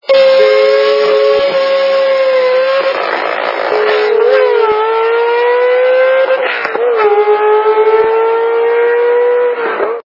» Звуки » Авто, мото » Звук - Ферарри
При прослушивании Звук - Ферарри качество понижено и присутствуют гудки.